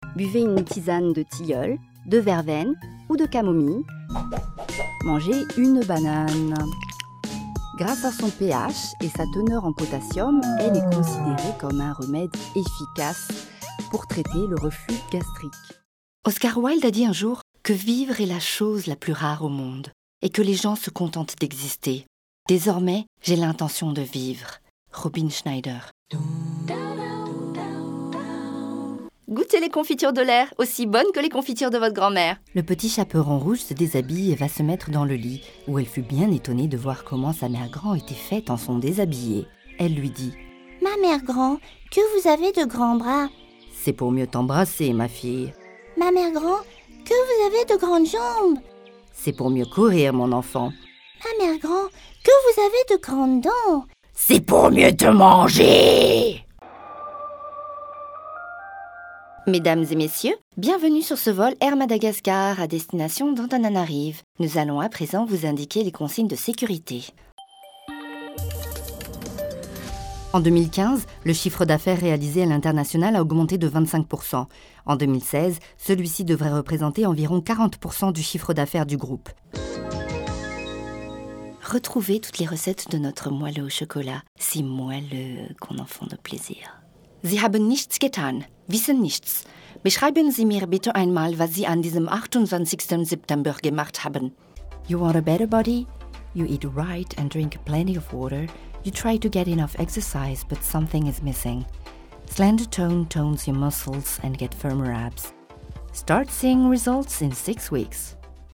Démo Voix off (français, allemand, anglais)